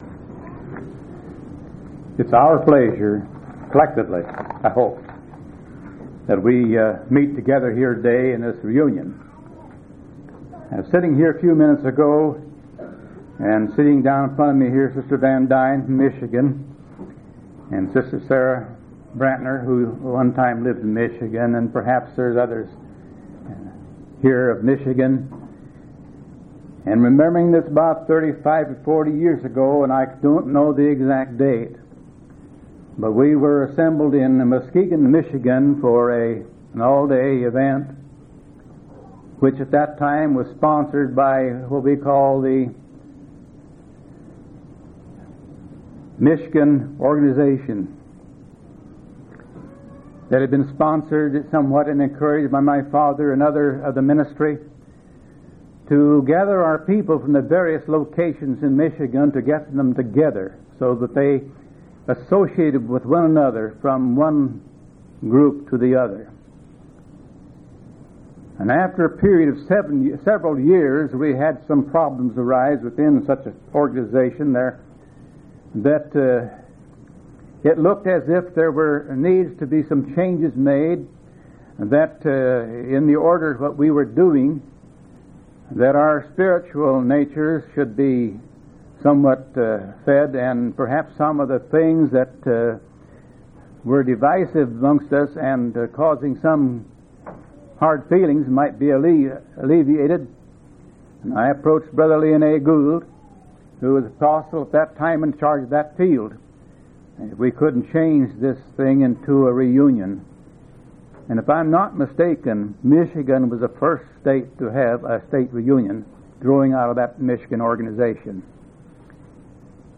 8/16/1986 Location: Missouri Reunion Event